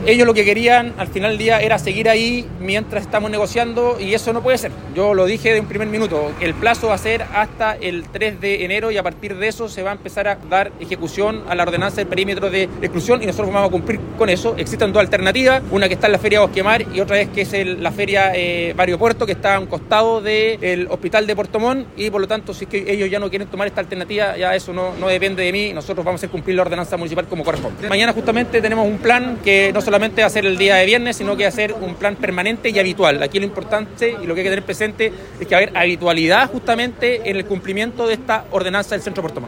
Ante ello, el alcalde, Rodrigo Wainraihgt, mencionó los posibles lugares donde se podrían reubicar.
rodrigo-wainraihgt-alcalde-pto-montt.mp3